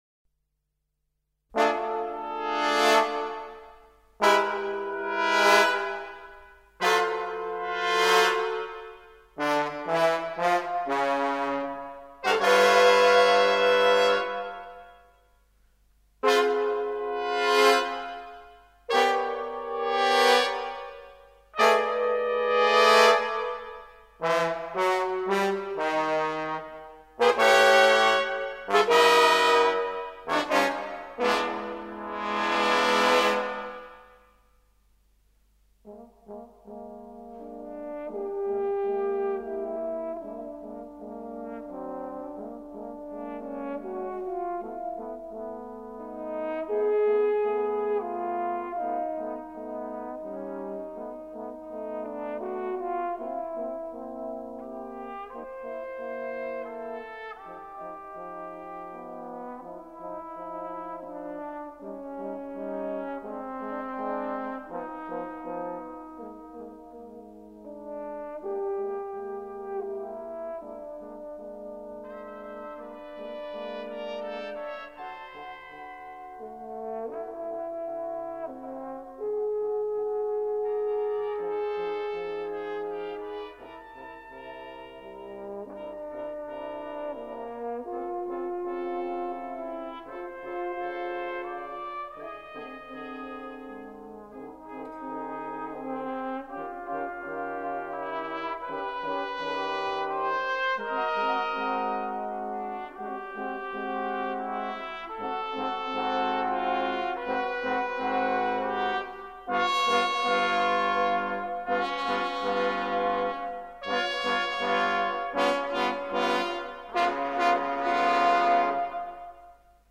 for brass quintet [1981/82] | duration: 10’00”
trumpet
horn
trombone